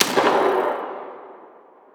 AutoGun_far_02.wav